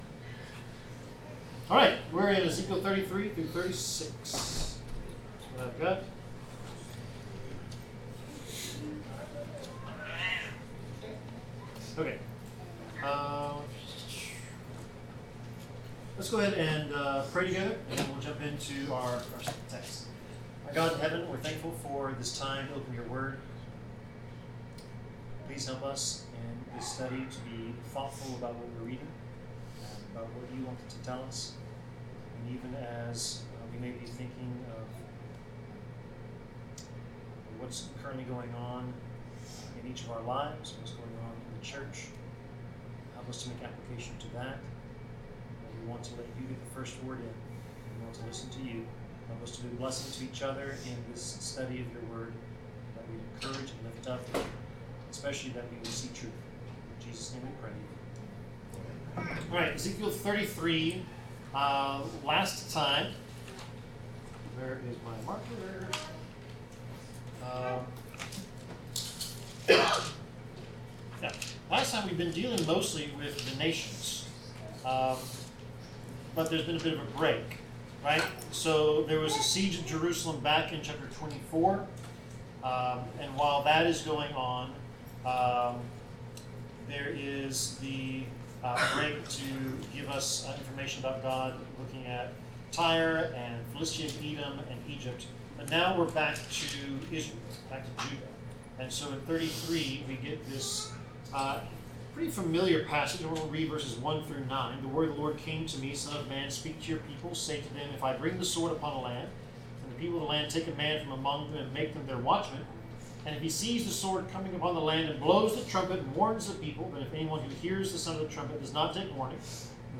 Bible class: Ezekiel 33-36
Service Type: Bible Class Topics: Consequences of Sin , God's Judgment , Jesus , Justice , Prophecy , Protection , Restoration , Righteousness , Shepherds , Sin , The Flock , Trusting in God